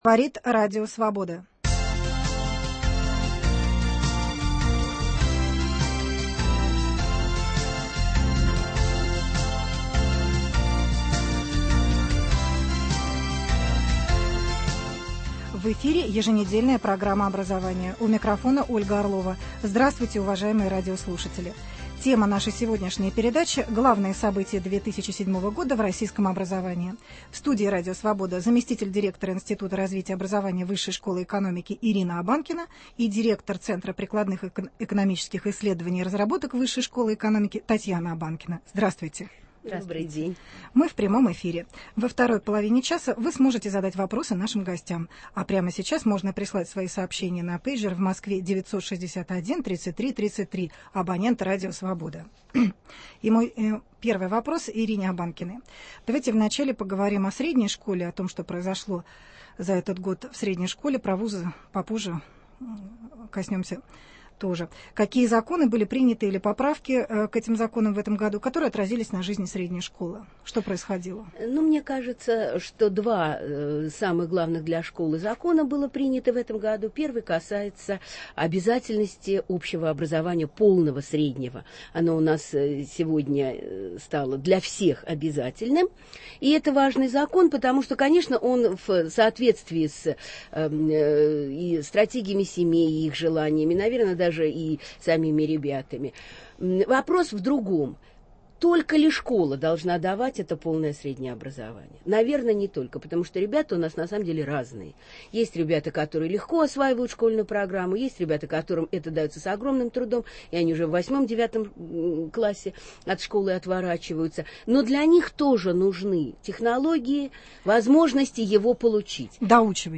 Главные события 2007 года в российском образовании. В студии Радио Свобода: